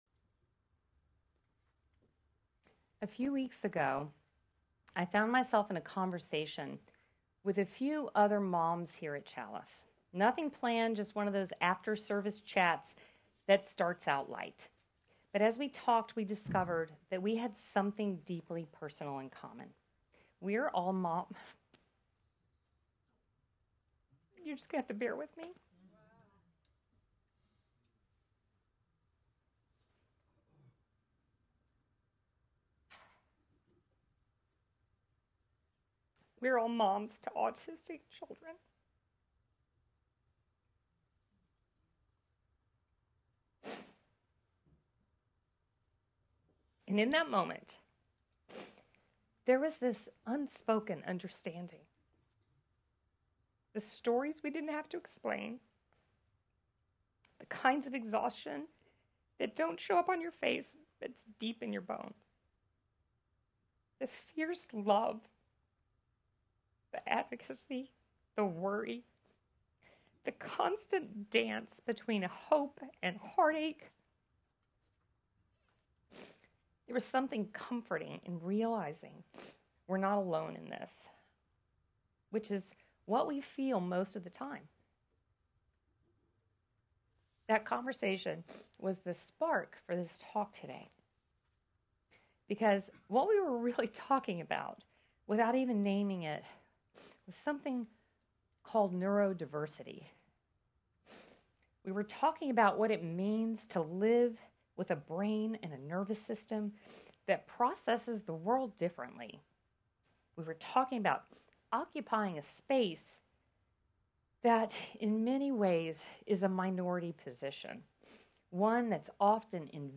This Mother’s Day, we come together to honor the beautiful complexity of the human mind and the many ways we move through the world. Through story, song, and shared reflection, we’ll celebrate neurodiversity as a vital part of our collective wholeness.